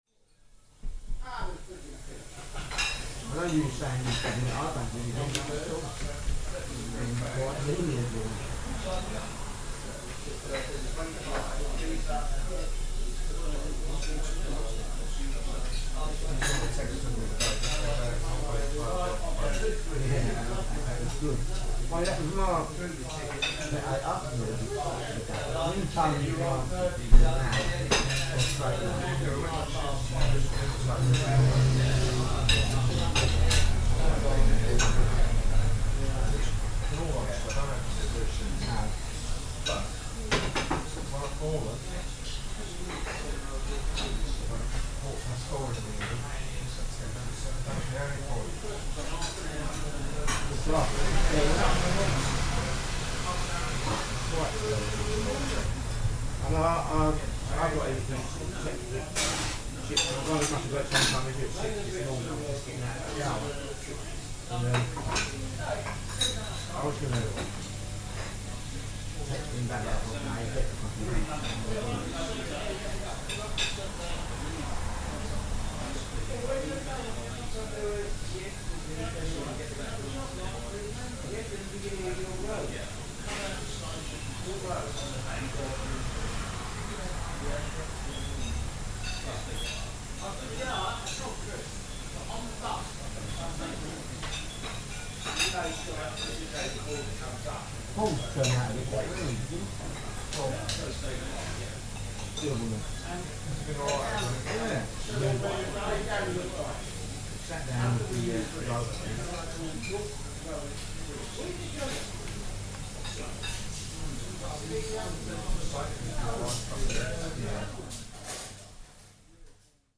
Double Six cafe Euston
Tags: Sound Map in London London sounds UK Sounds in London London